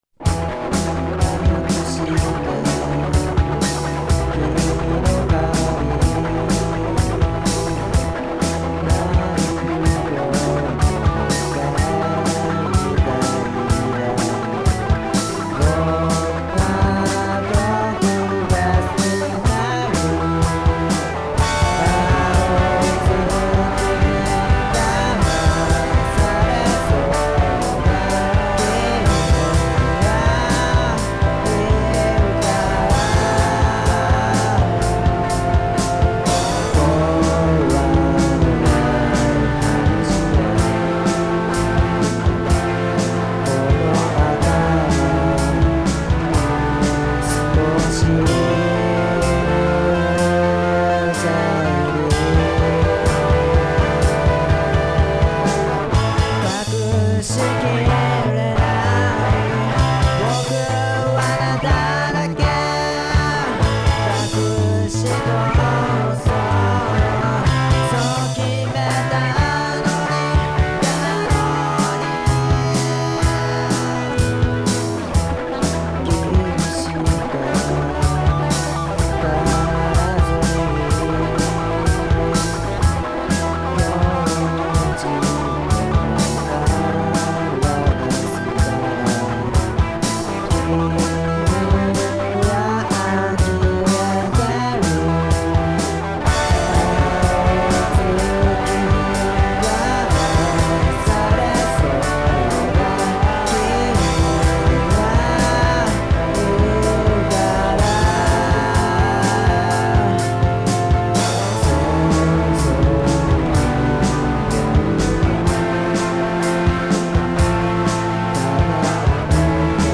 メンバーがみつからないのでバンドをあきらめてひとりで制作したアルバム。
BOSSのふつうのドラムマシンでやってます。